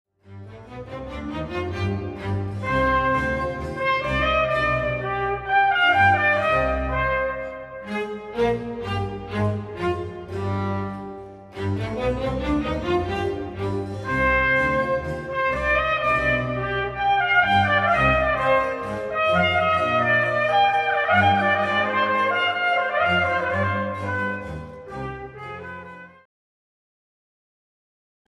für Trompete (orig. Oboe), Streicher & B.c.
Trompete
Violine
Viola
Violoncello
Contrabass
Basso Continuo